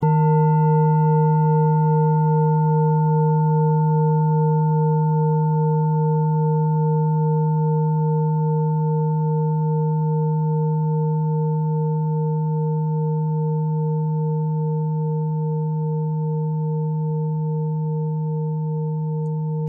Klangschale Bengalen Nr.39
Sie ist neu und wurde gezielt nach altem 7-Metalle-Rezept in Handarbeit gezogen und gehämmert.
(Ermittelt mit dem Filzklöppel)
Hören kann man diese Frequenz, indem man sie 32mal oktaviert, nämlich bei 154,66 Hz. In unserer Tonleiter befindet sich diese Frequenz nahe beim "D".
klangschale-ladakh-39.mp3